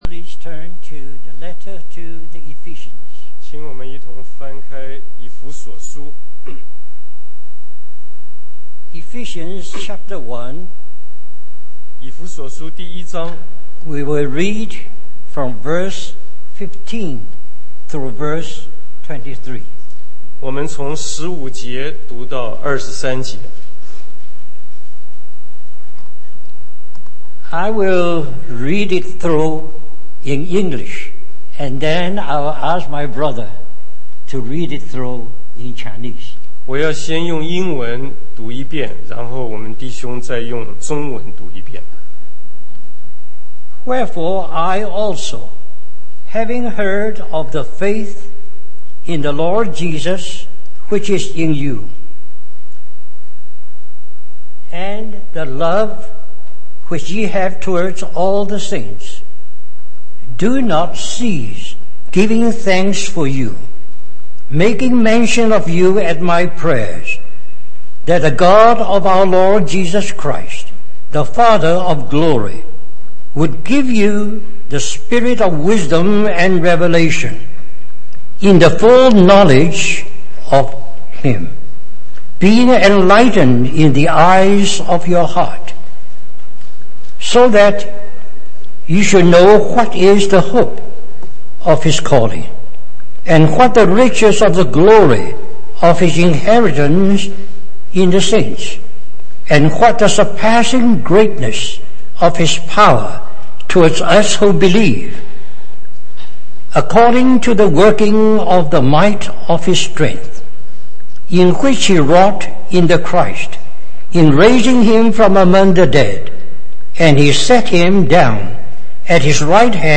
In this sermon, the preacher emphasizes the importance of revelation and understanding in the spiritual journey. He compares it to developing a film, where the picture is revealed after washing it with liquid.